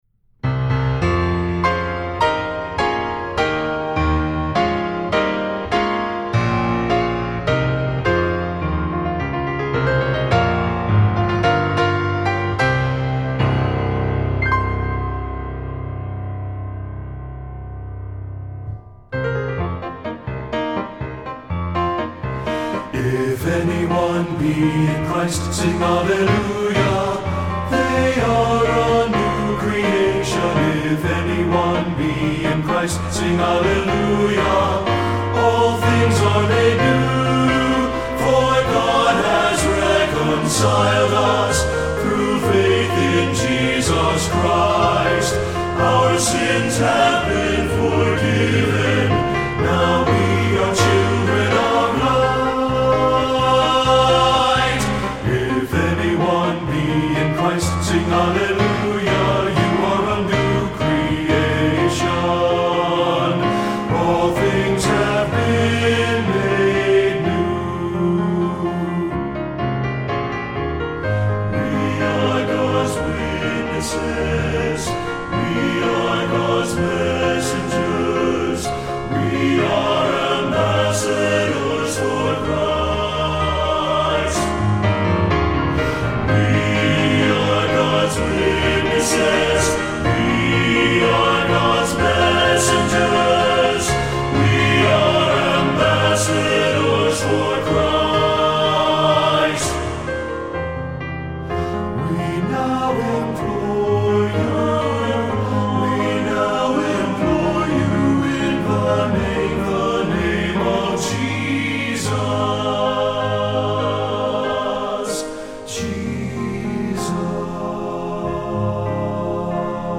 Voicing: TTBB